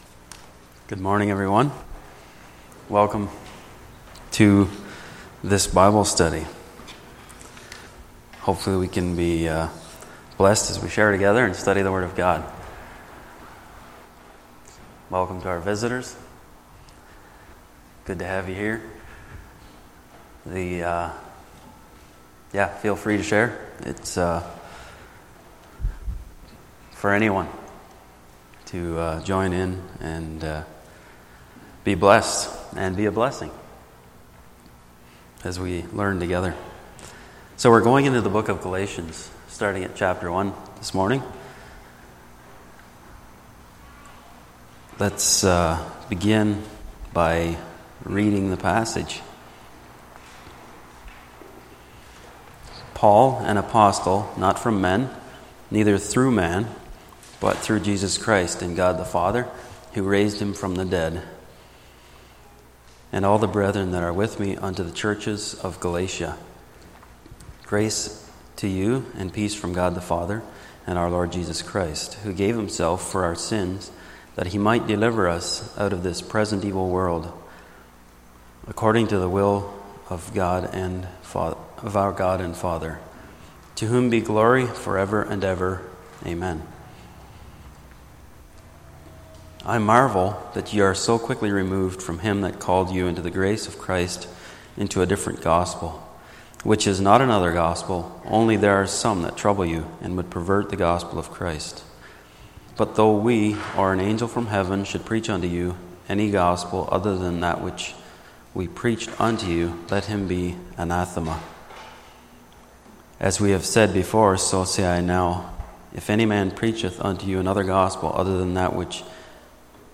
Sunday Morning Bible Study Service Type